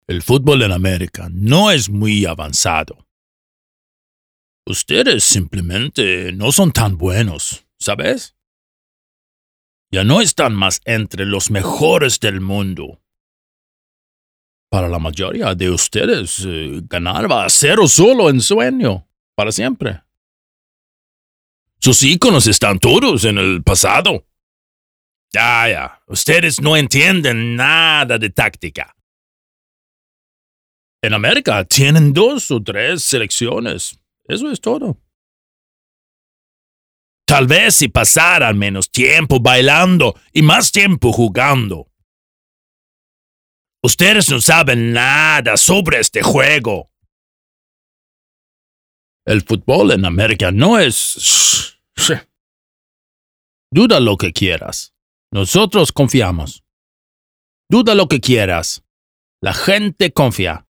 Male
Confident, Warm, Versatile
Microphone: AKG c414b-uls, Shure SM7